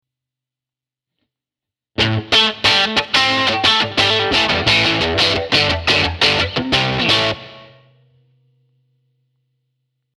In the first measure we play our original blues riff, but in the second measure we are playing a straight on rock riff.
Here is an example of such a blues rock riff: